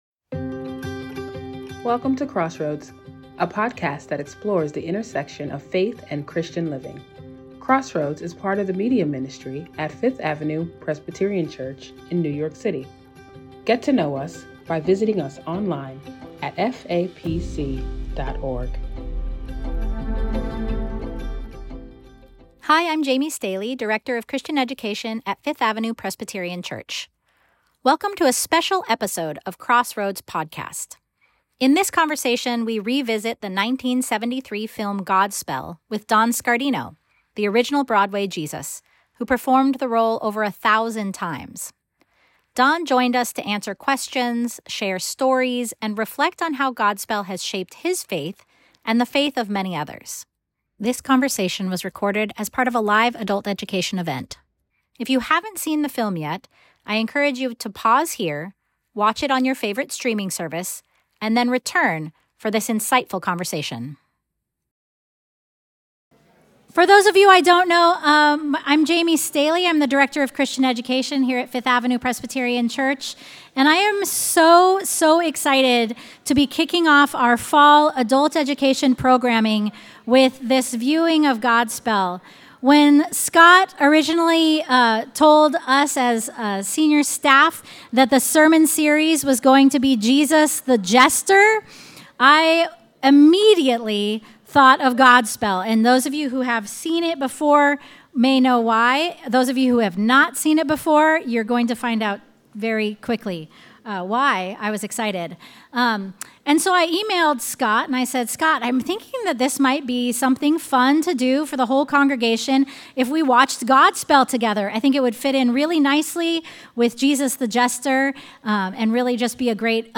Podcasts Crossroads #40 · October 6, 2025 Episode 40: Godspell: A Live Q+A with Don Scardino Download Episode In this special episode of Crossroads we share a live Q&A from our all-church Godspell movie event with Don Scardino, who played Jesus in over 1,000 performances on and off Broadway. Hear stories of how this joyful musical continues to inspire faith, laughter, and a fresh vision of Jesus’ radical love.